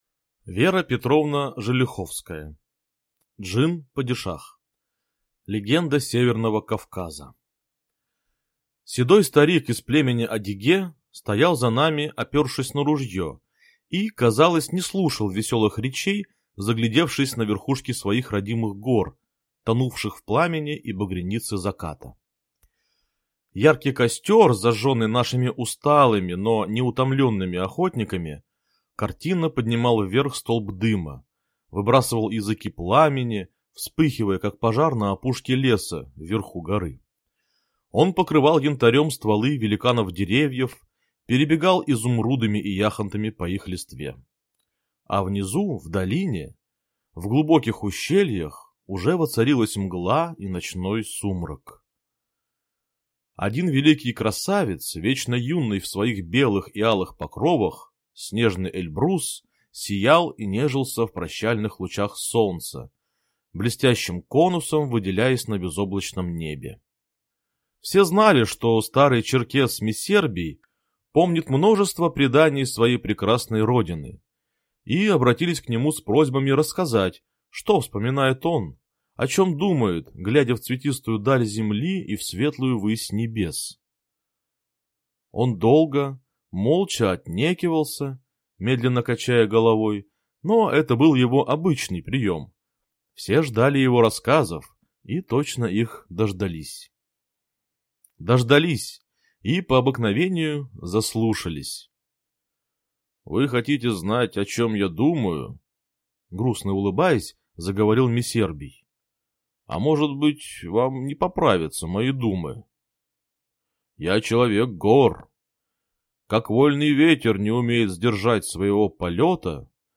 Аудиокнига Джин-Падишах | Библиотека аудиокниг